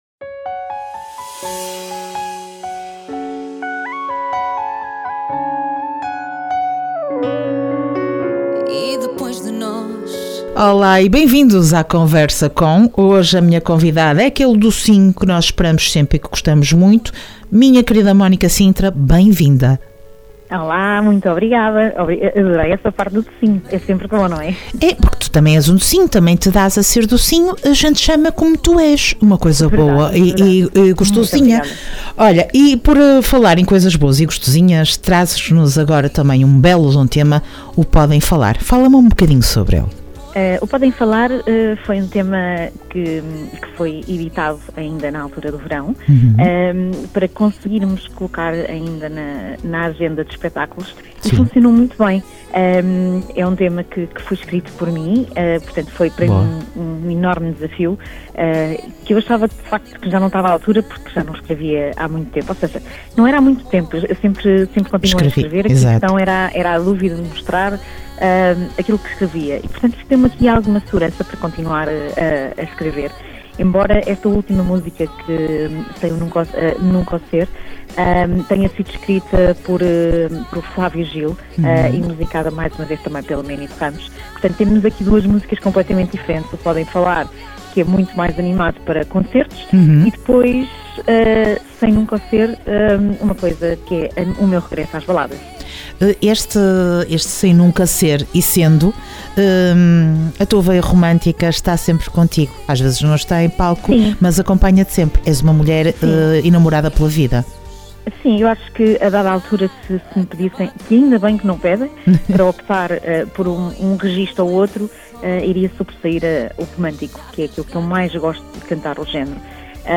Entrevista Mónica Sintra dia 9 de março.